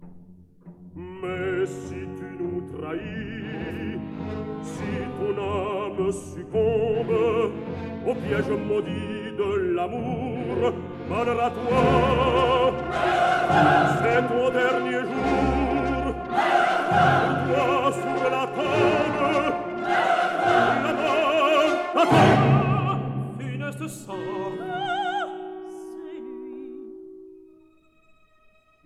soprano
tenor
baritone
bass
Chorus and orchestra of the